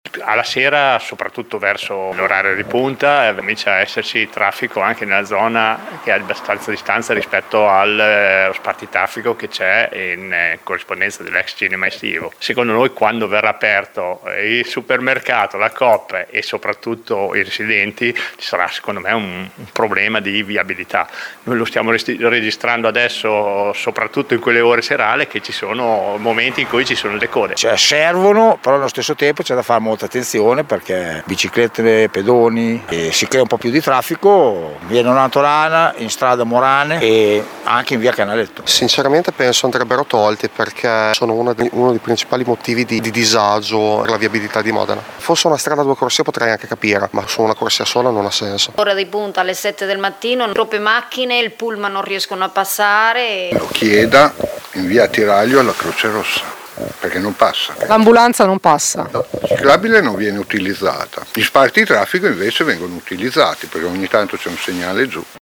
Abbiamo raccolto il parere di alcuni automobilisti